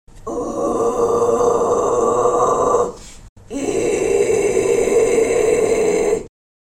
D’un point de vue stylistique, les False Cord Screams sont souvent graves et possèdent une qualité gutturale, souvent bestiale, brutale ou monstrueuse.
False Cord Scream - phonation soufflée